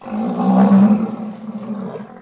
c_rhino_bat3.wav